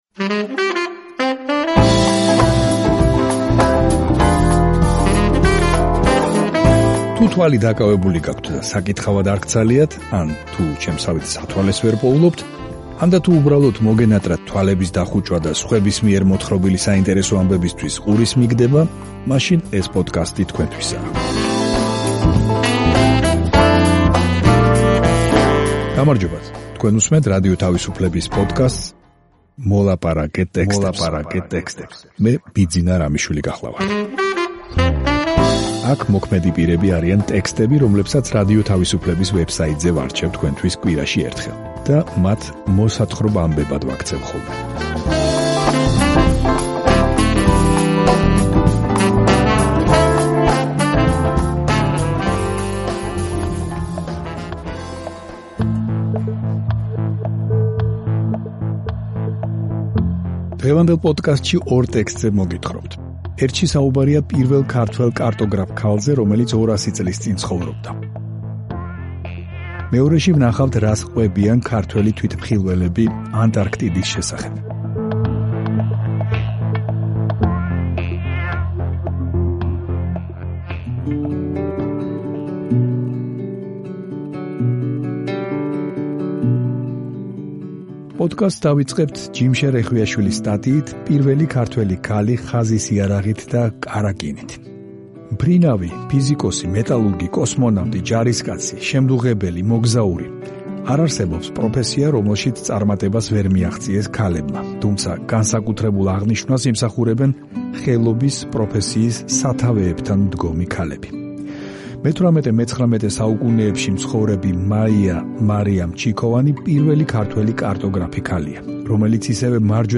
თუ თვალი დაკავებული გაქვთ და საკითხავად არ გცალიათ, ან თუ სათვალეს ვერ პოულობთ, ანდა, თუ უბრალოდ მოგენატრათ თვალების დახუჭვა და სხვების მიერ მოთხრობილი საინტერესო ამბებისთვის ყურის მიგდება, მაშინ ეს პოდკასტი თქვენთვისაა.